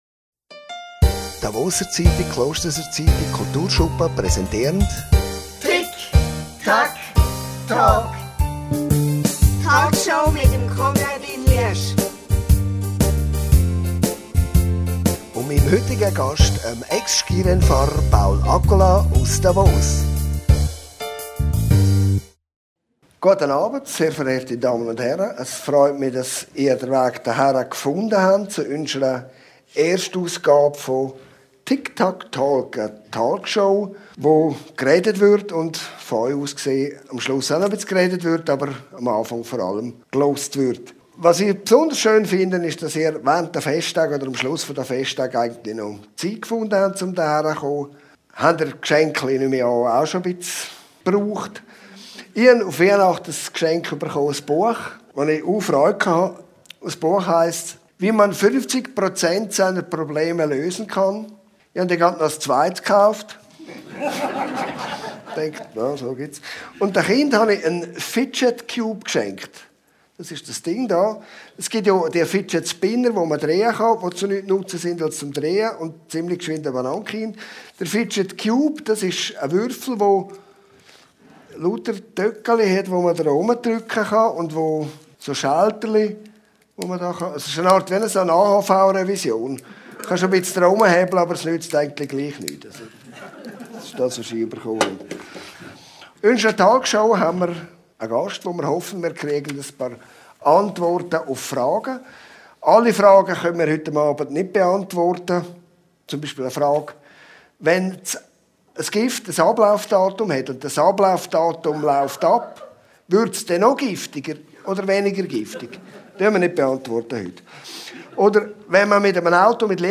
Talkshow und Podcast, präsentiert von der «Davoser Zeitung», «Klosterser Zeitung», Kulturschuppen Klosters. Gast ist der Ex-Skirennfahrer Paul Accola.
Dabei werden heitere wie ernste Themen, Spannendes und Nebensächliches, auf lockere Art und Weise thematisiert. Und das Publikum kann Fragen stellen.